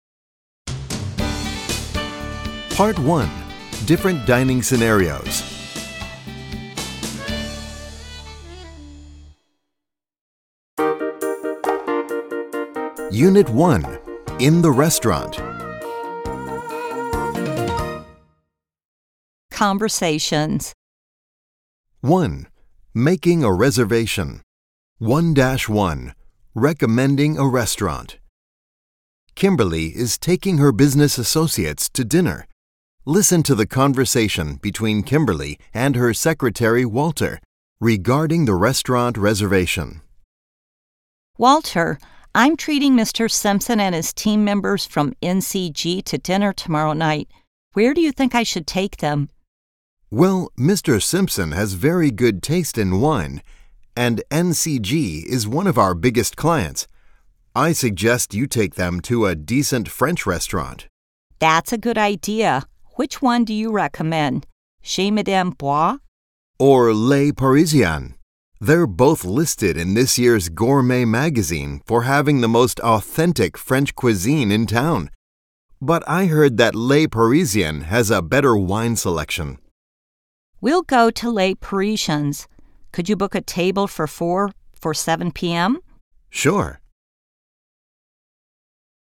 ★專業外師親錄MP3，精進餐飲英語聽說實力
全書皆有外師親錄MP3音檔，針對會話與句型加強聽力訓練，搭配寂天雲MP3 APP隨走隨聽、有效提升餐飲英語聽說能力。